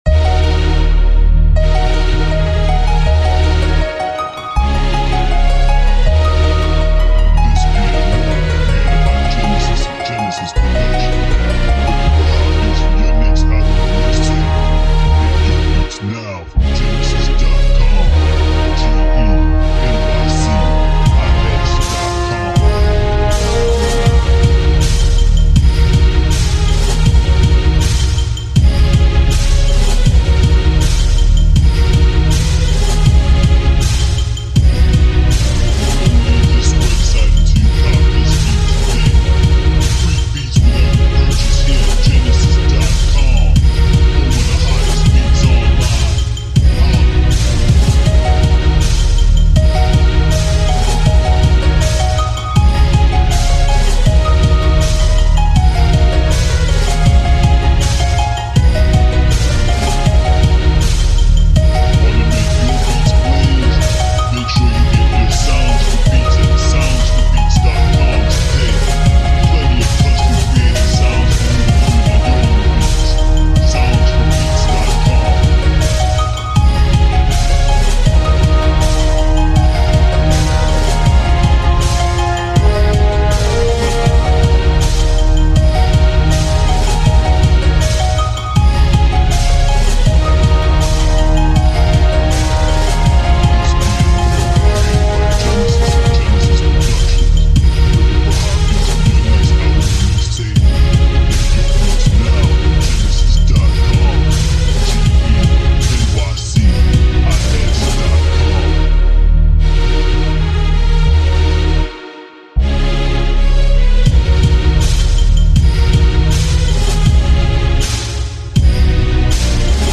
Epic Battle Type War Beat